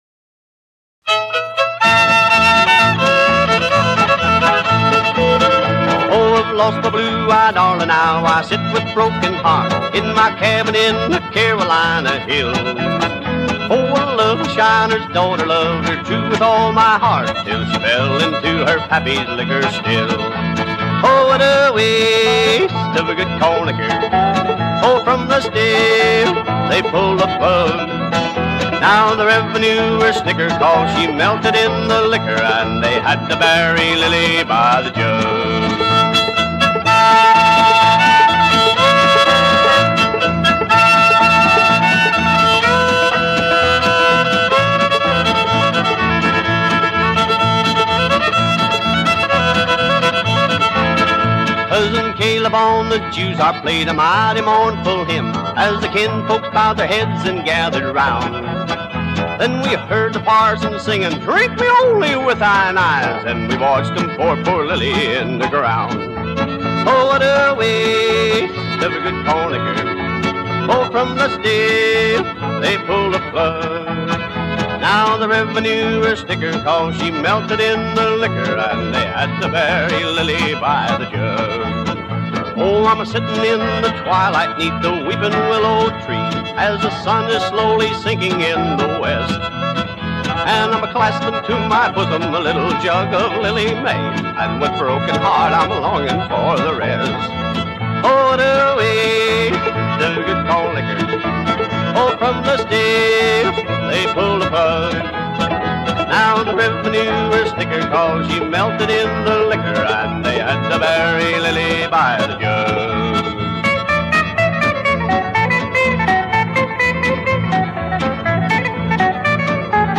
More 1940s/50s recordings from this legendary label.
With the start of Rockabilly heard within.